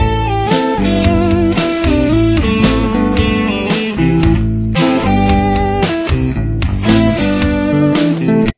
Intro Riff